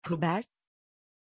Aussprache von Hubert
pronunciation-hubert-tr.mp3